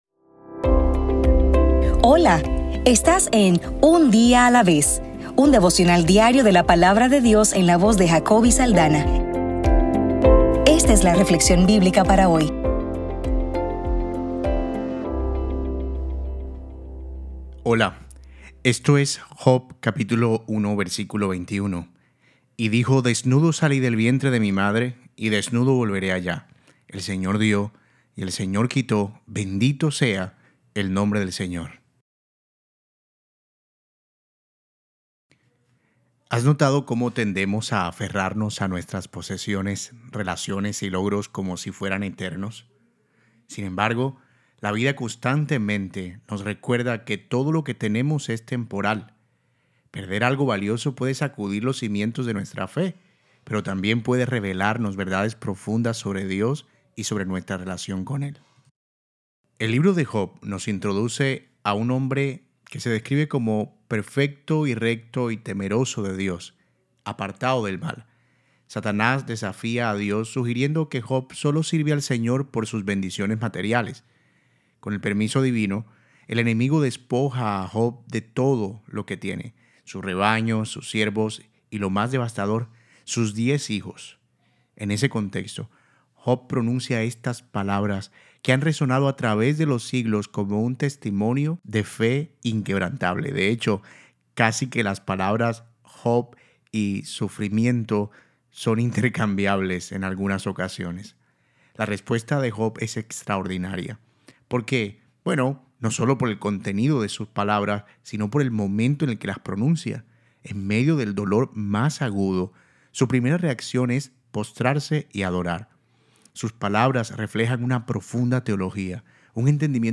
Devocional para el 2 de febrero